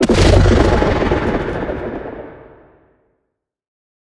Minecraft Version Minecraft Version snapshot Latest Release | Latest Snapshot snapshot / assets / minecraft / sounds / mob / warden / sonic_boom4.ogg Compare With Compare With Latest Release | Latest Snapshot
sonic_boom4.ogg